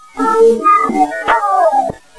recorded at the battlefield.
This next EVP is a little startling because it is so clear.
It is most definatly the voice of a small child....